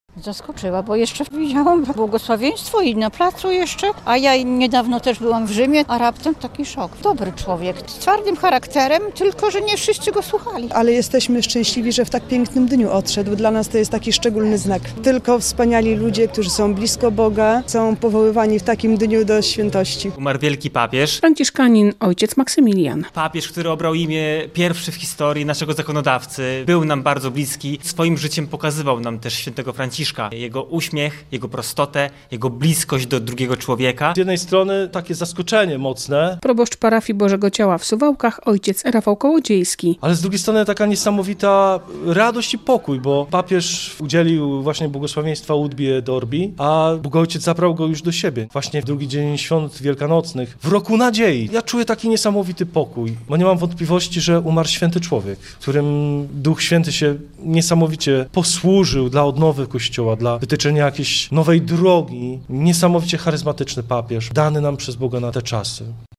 Suwalczanie wspominają zmarłego Franciszka -relacja